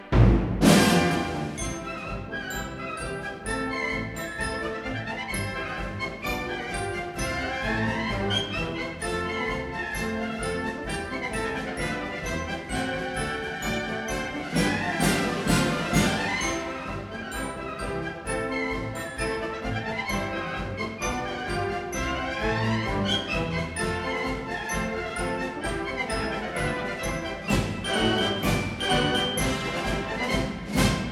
a 1958 stereo recording